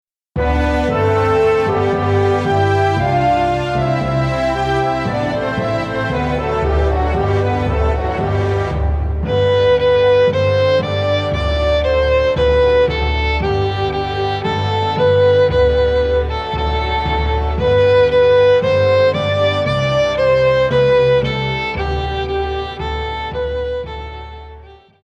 Recueil pour Violon